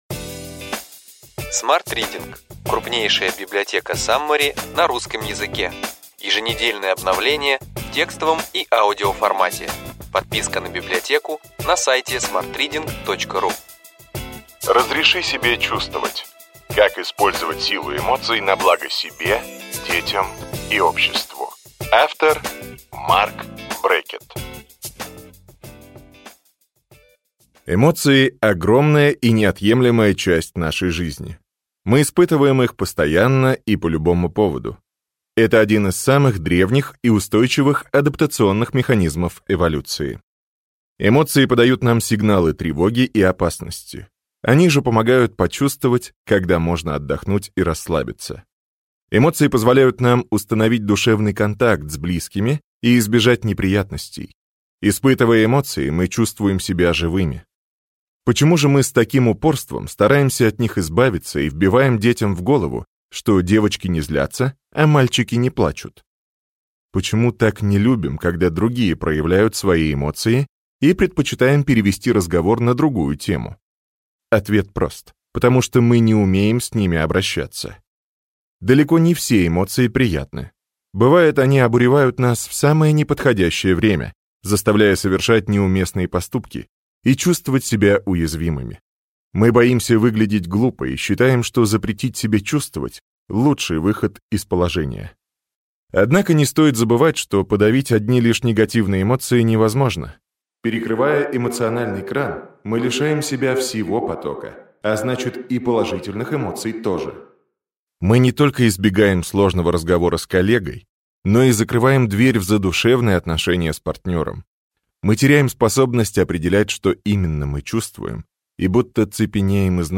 Аудиокнига Ключевые идеи книги: Разреши себе чувствовать. Как использовать силу эмоций на благо себе, детям и обществу. Марк Брэкетт | Библиотека аудиокниг